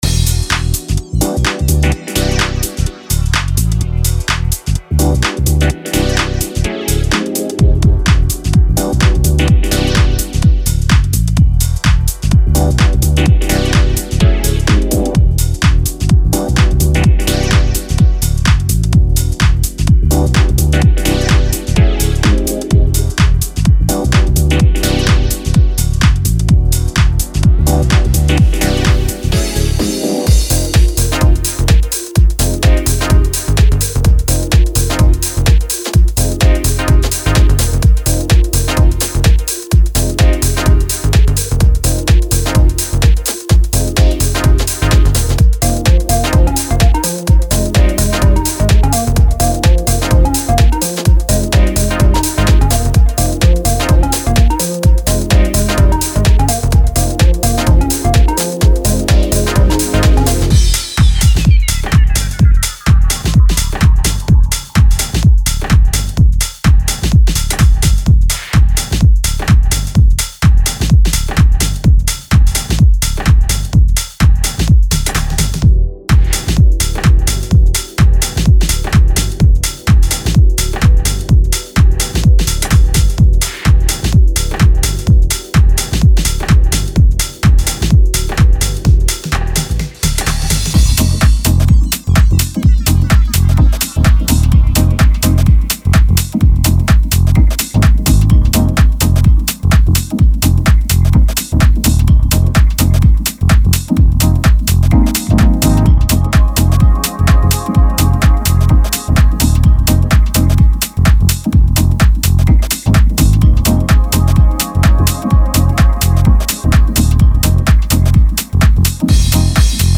デモサウンドはコチラ↓
Genre:Deep House
35 Bass Loops
31 Synth Loops
15 Pad Loops
14 Percussion Loops